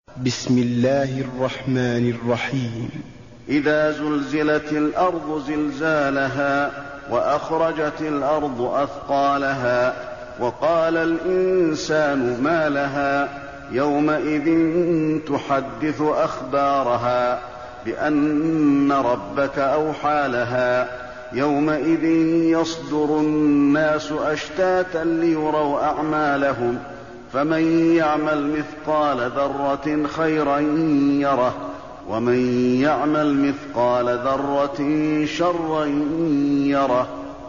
المكان: المسجد النبوي الزلزلة